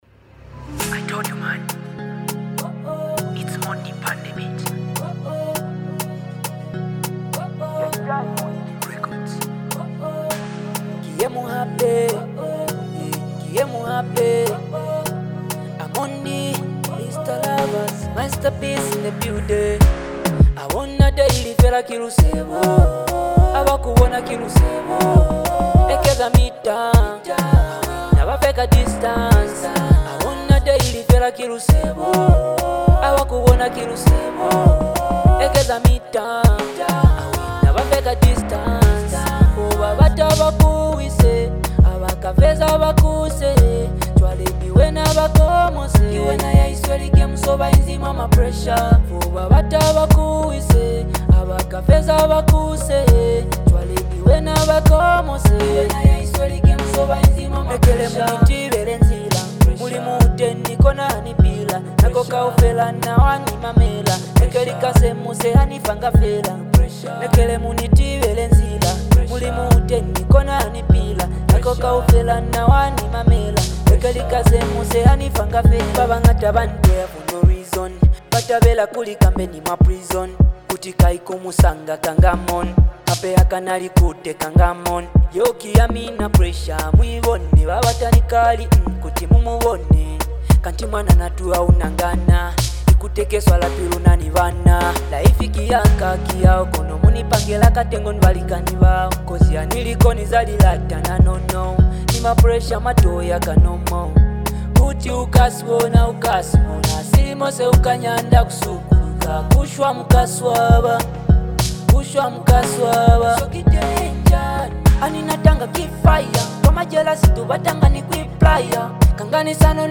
Zambian Mp3 Music